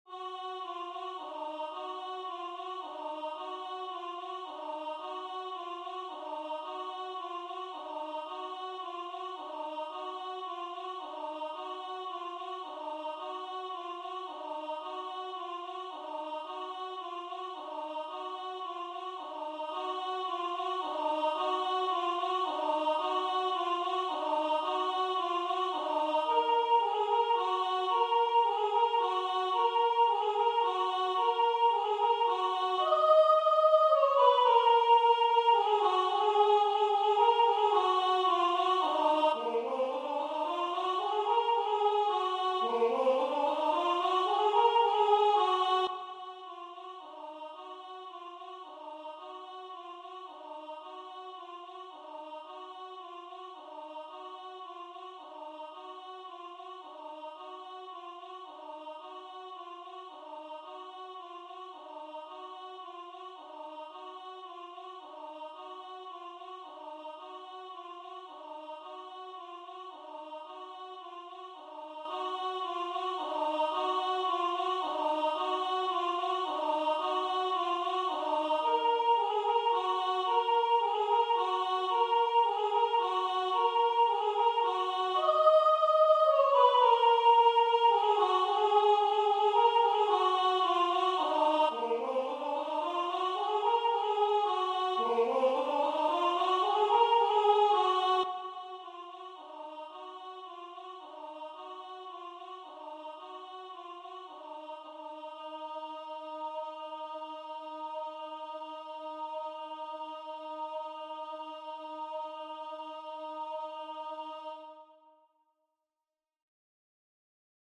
MP3 version voix synth.
Soprano